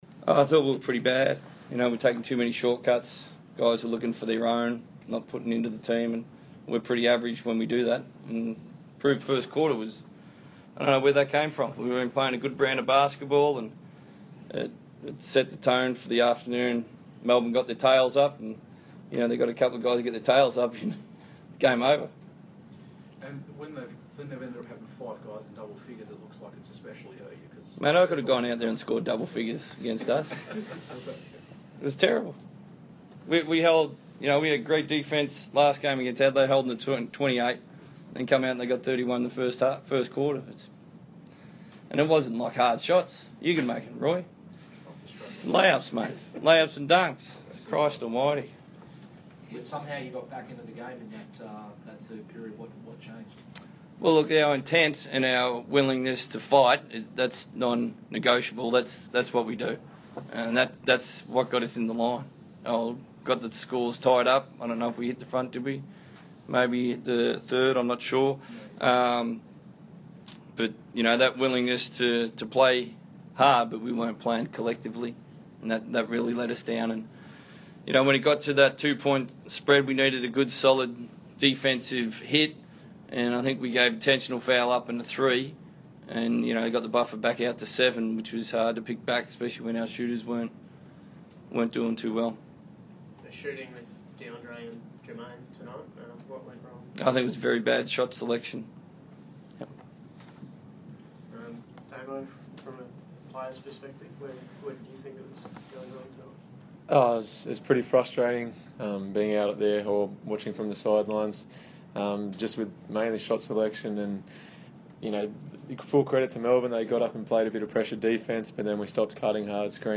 Damian Martin and Trevor Gleeson speak to the media after going down to Melbourne United.